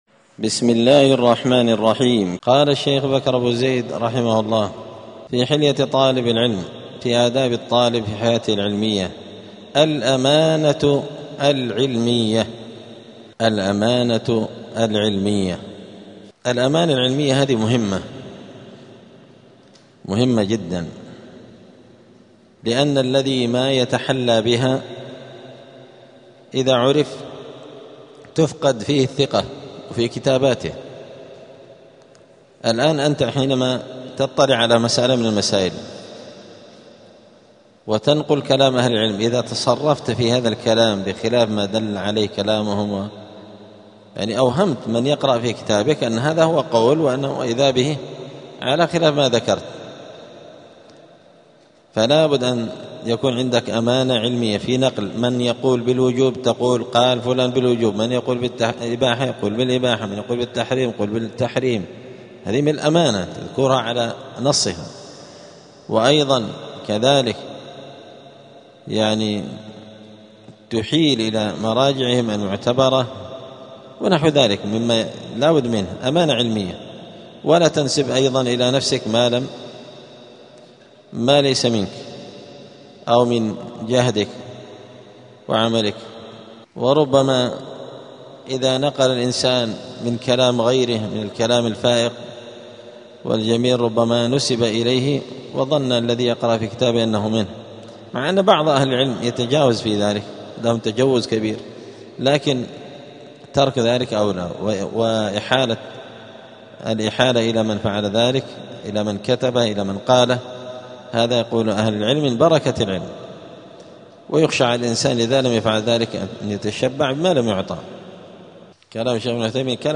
الجمعة 20 رجب 1447 هــــ | الدروس، حلية طالب العلم، دروس الآداب | شارك بتعليقك | 5 المشاهدات